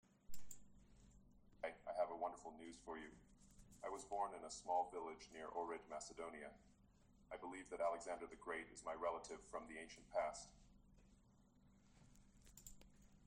Вака би звучел еден кус разговор меѓу Илон Маск и Морган Фримен.